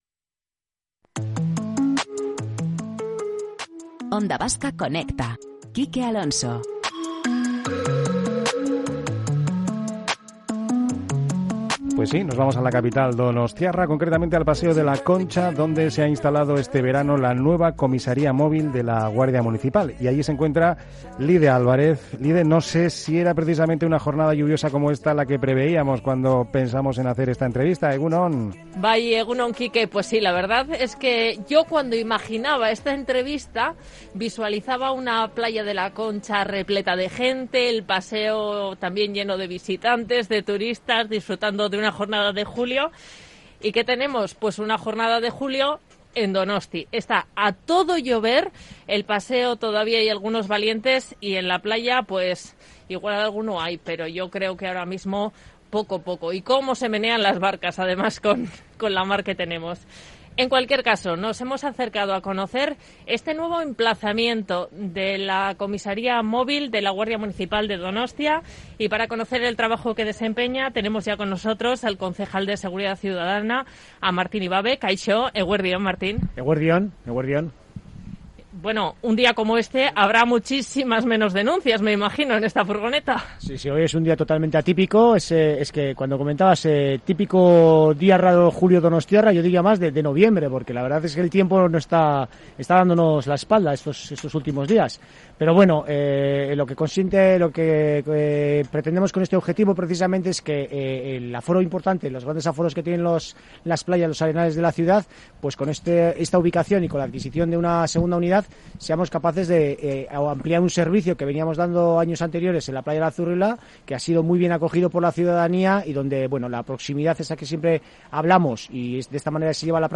Tomando el pulso a la actualidad en el mediodía más dinámico de la radio.